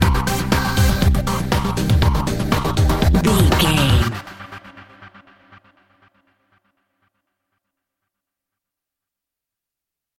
Aeolian/Minor
drum machine
synthesiser
electric piano
90s
Eurodance